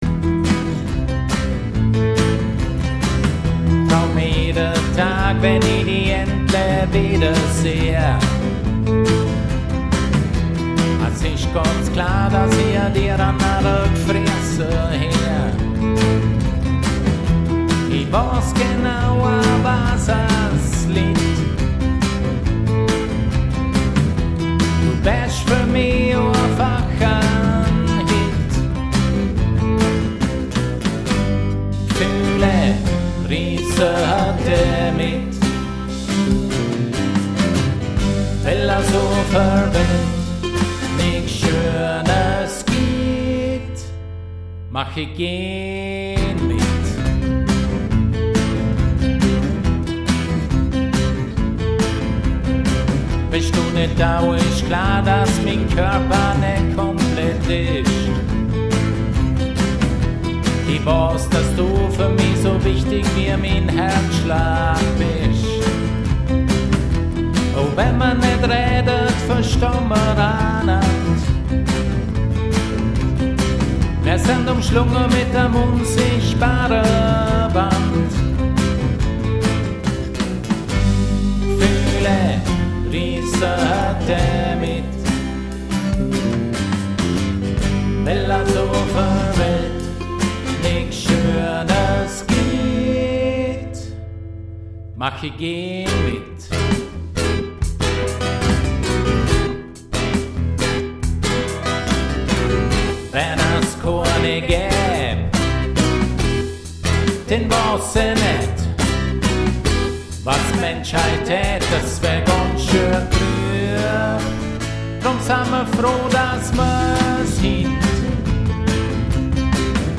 Handgemachte Musik im Bregenzerwälder-Dialekt